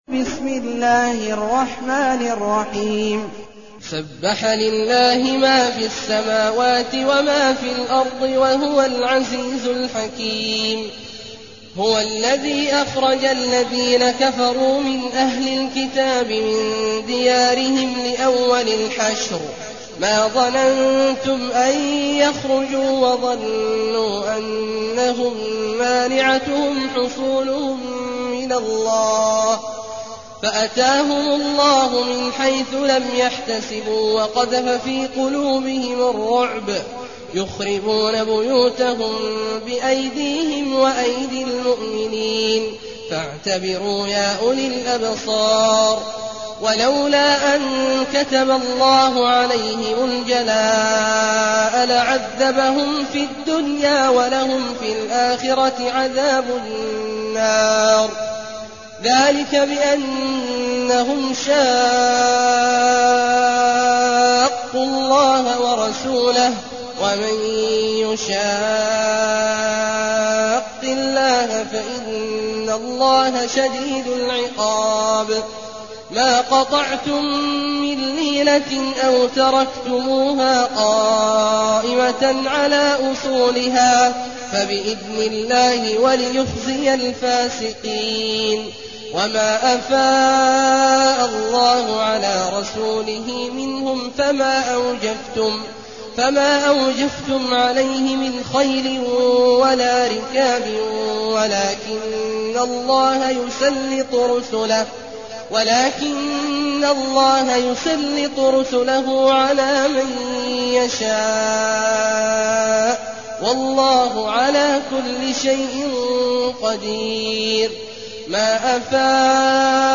المكان: المسجد النبوي الشيخ: فضيلة الشيخ عبدالله الجهني فضيلة الشيخ عبدالله الجهني الحشر